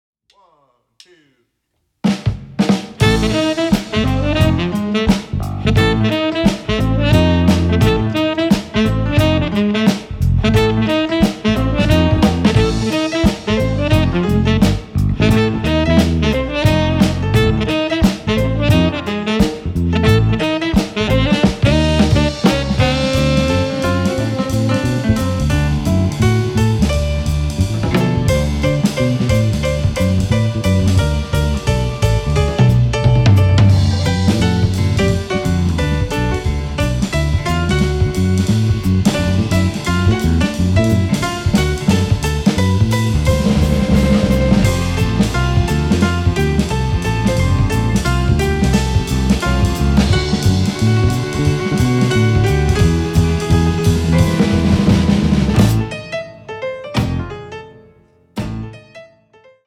Drums
Piano
Bass
Sax
Trombone
Cello
Violin
Vocals